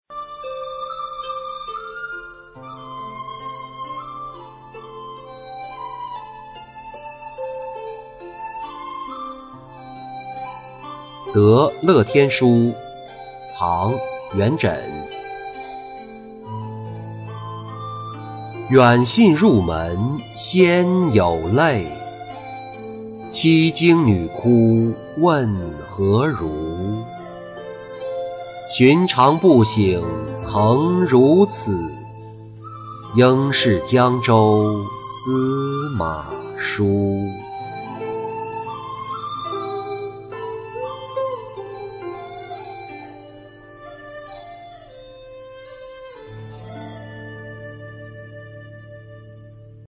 得乐天书-音频朗读